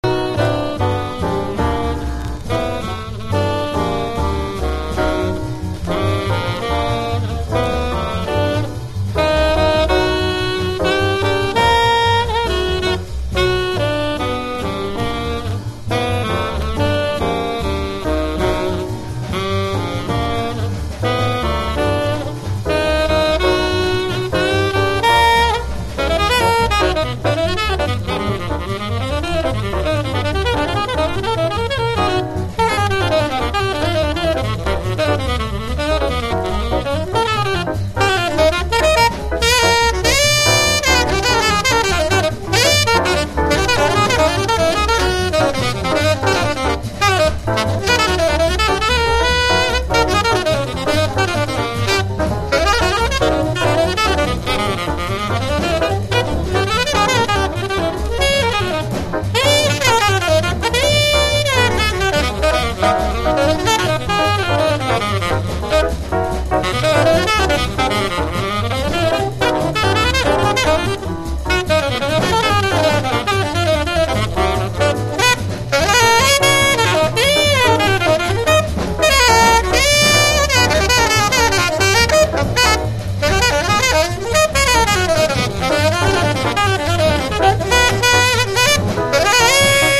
ハードバップ